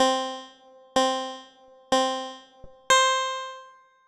Countdown (2).wav